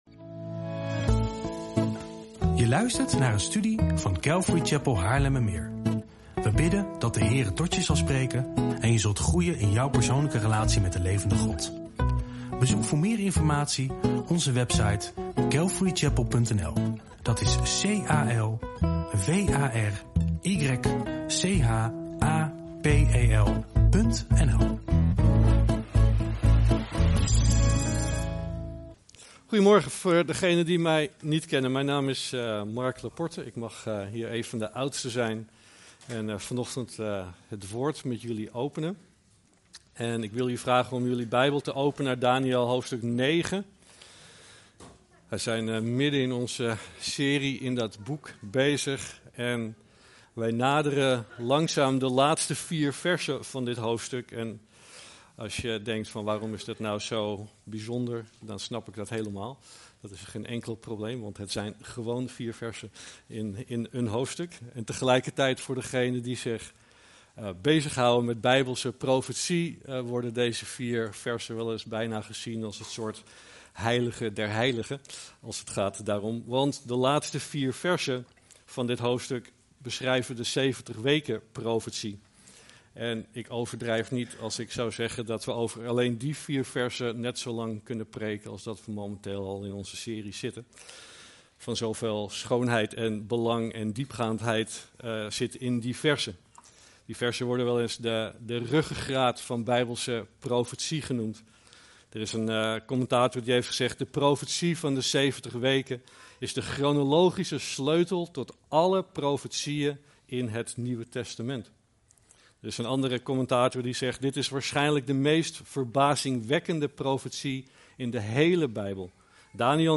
[CC Haarlemmermeer, 8 maart 2026]
PREKENSERIE